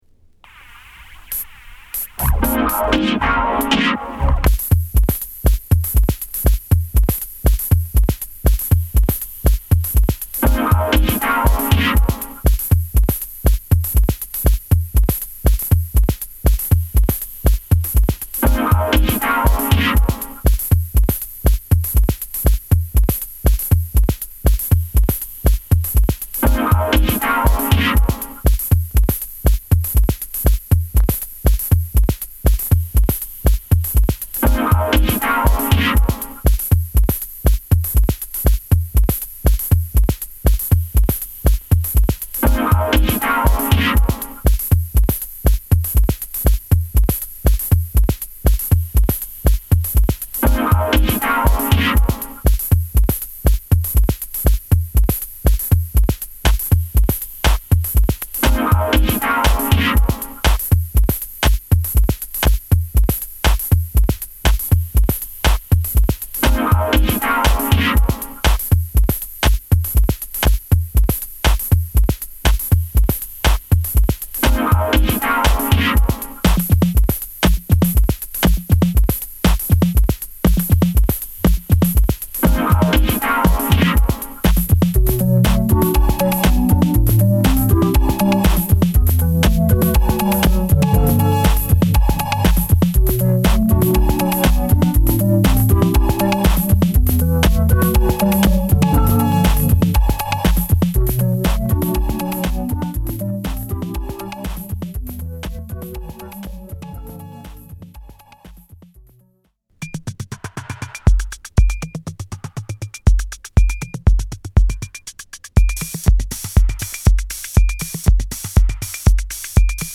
ハイハットの走ったリズミカルなドラムに丸みを帯びたシンセリードが浮遊感を煽るA2
ロウなドラムグルーヴに中盤からヴィブラフォン/キーボード/シンセが幻想的な響きを聴かせるB1
メローで幻想的な鍵盤類とヴィブラフォン、ピアノのコンビネーションでダンサンブルにカヴァーしたB2